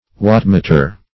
Wattmeter \Watt"me`ter\, n. [Watt + meter.] (Physics)